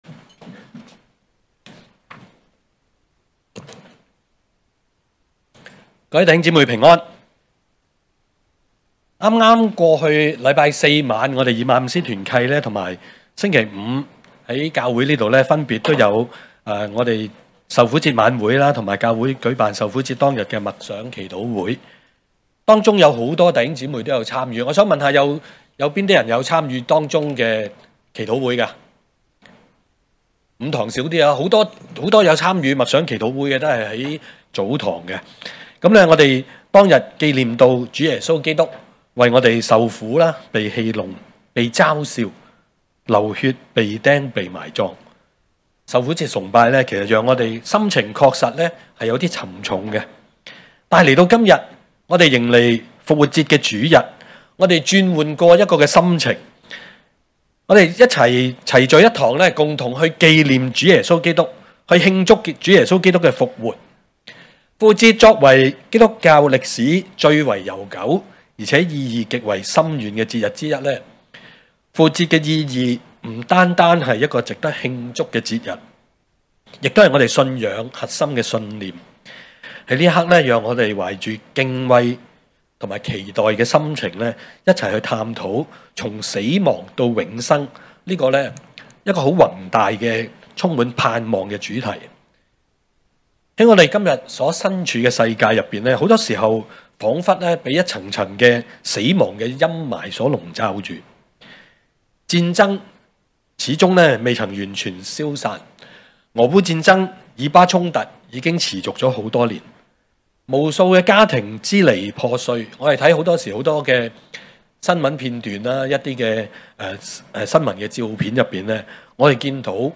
證道集
恩福馬鞍山堂崇拜-早、午堂